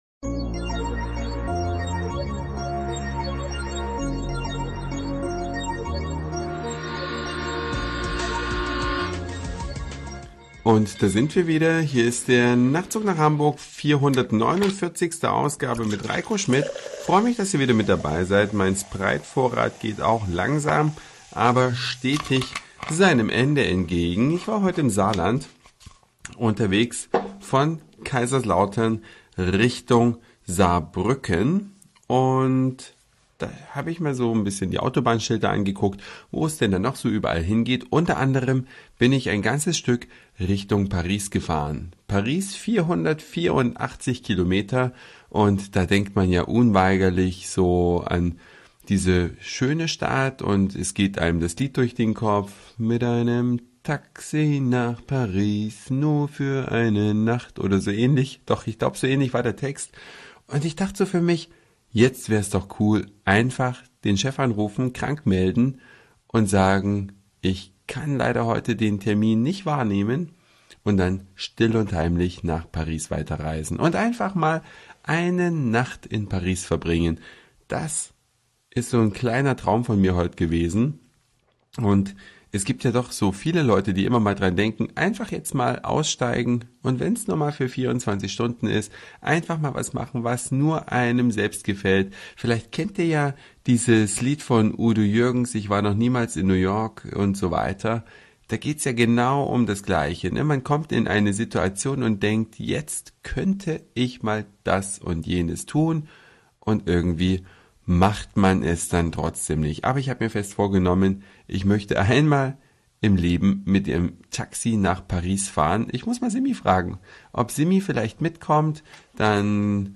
Eine Reise durch die Vielfalt aus Satire, Informationen, Soundseeing und Audioblog.
Unterwegs im Saarland.